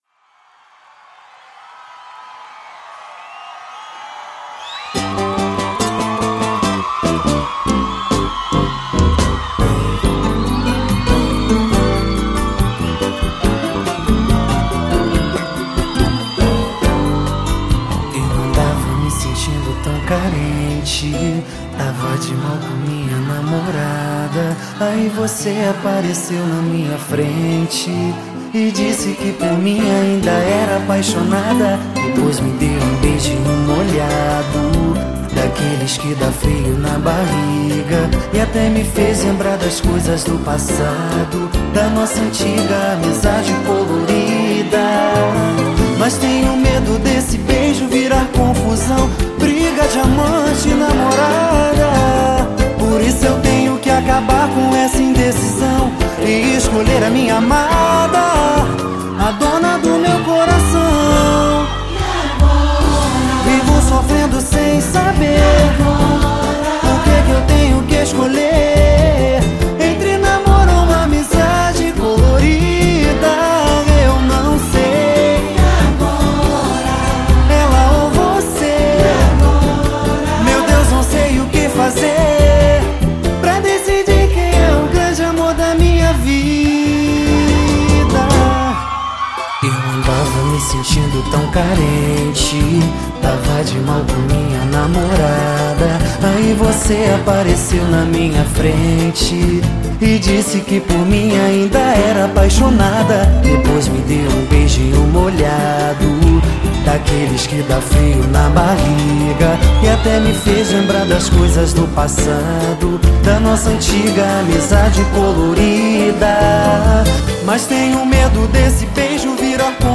EstiloPagode